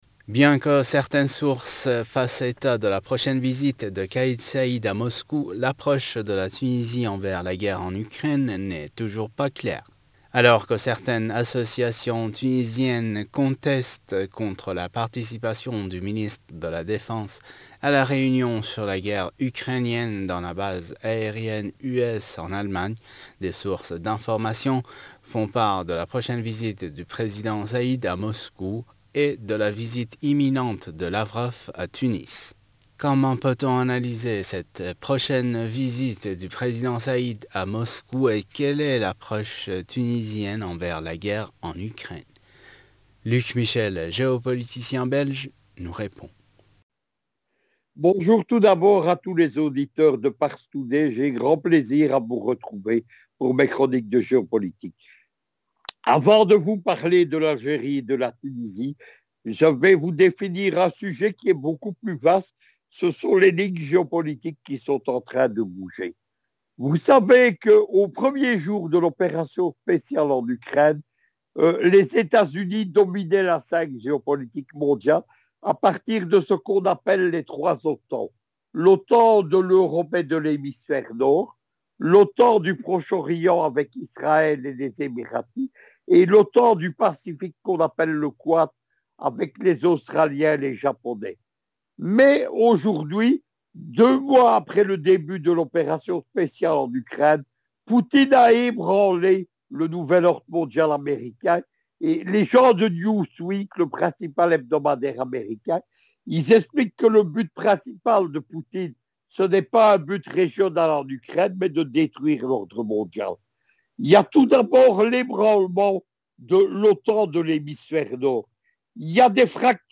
géopoliticien belge nous répond.